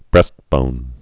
(brĕstbōn)